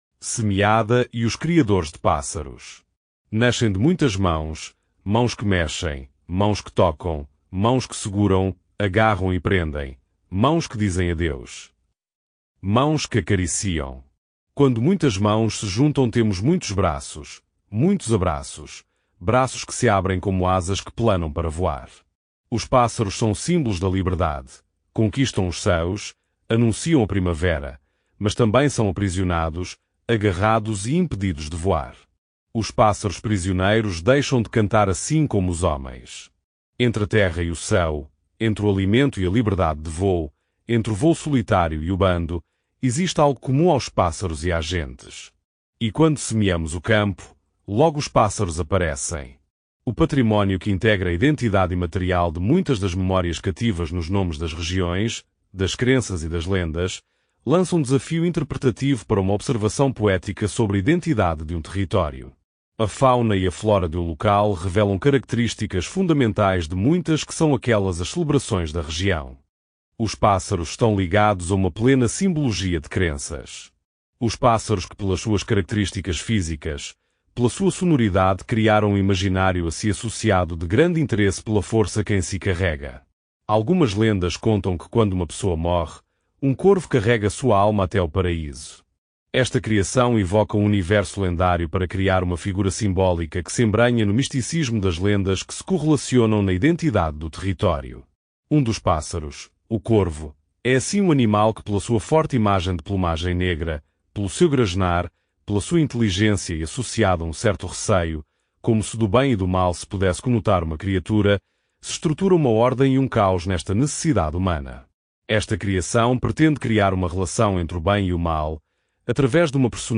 este áudio guia possui 13 faixas e duração de 00:19:08, num total de 13.6 Mb